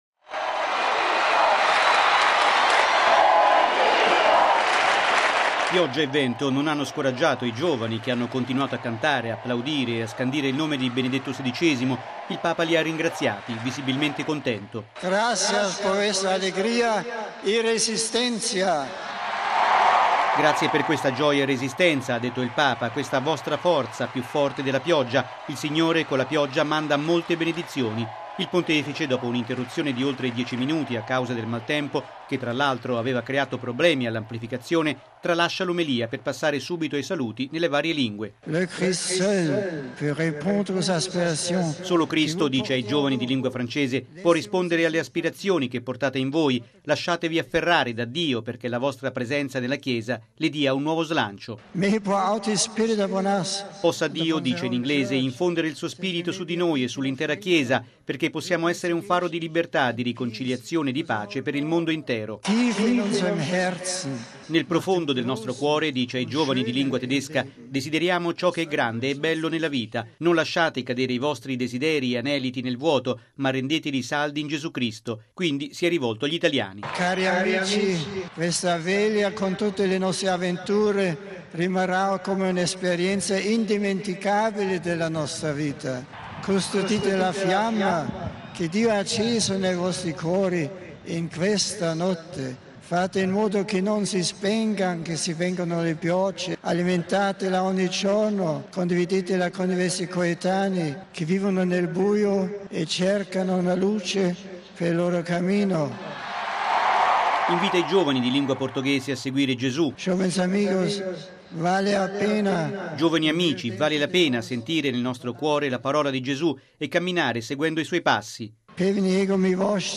◊   Una Veglia indimenticabile, ieri sera, quella della Giornata mondiale della Gioventù di Madrid, all’aeroporto di Cuatro Vientos, per la gioia, l’entusiasmo e poi per il profondo raccoglimento dei due milioni di giovani durante l’Adorazione eucaristica.